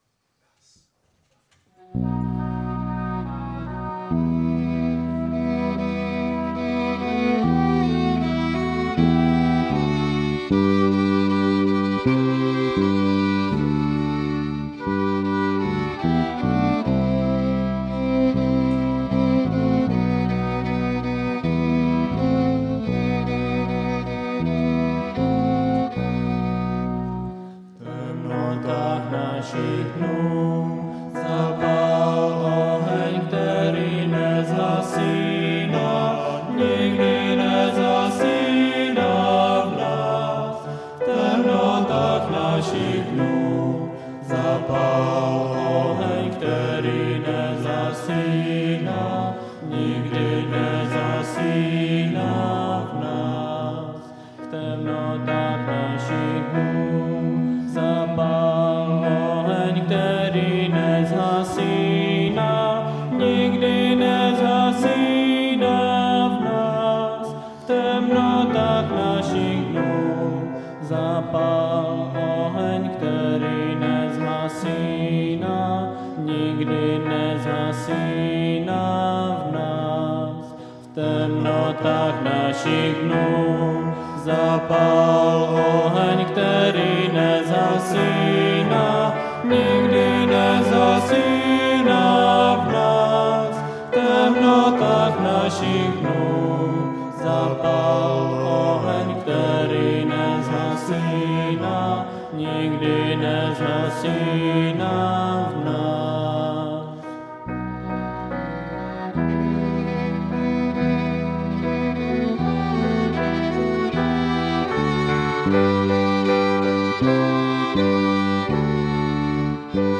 Velkopáteční bohoslužba